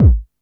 DrKick65.wav